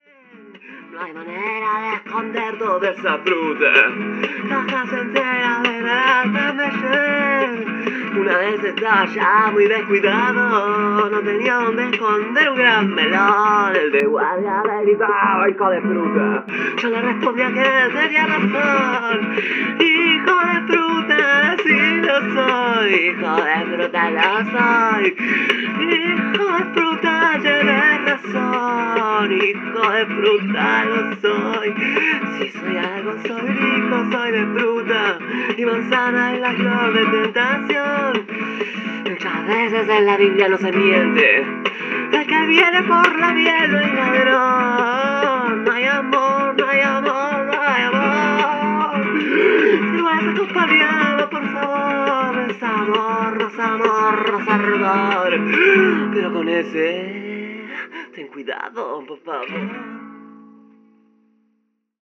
Un cover que hice